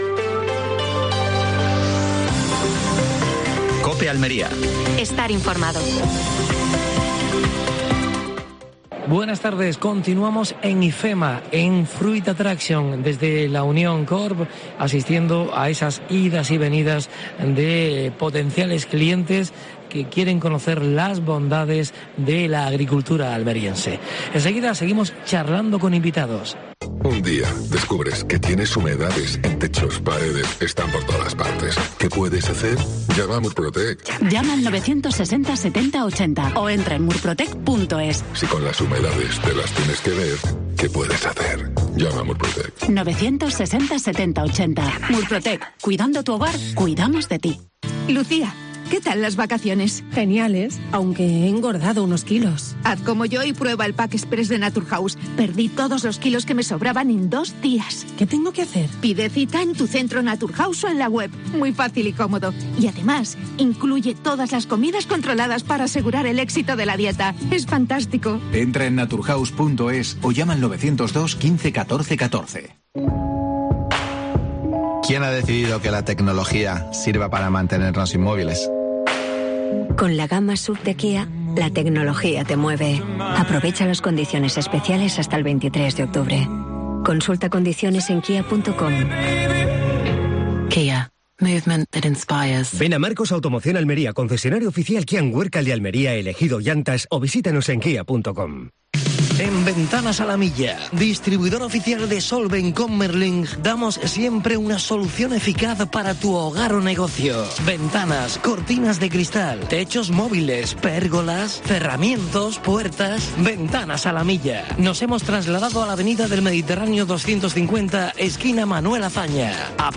AUDIO: Programa especial desde Fruit Attraction (Madrid). Entrevista al alcalde de Níjar.